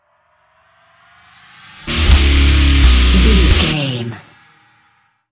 Aeolian/Minor
E♭
drums
electric guitar
bass guitar
hard rock
aggressive
energetic
intense
nu metal
alternative metal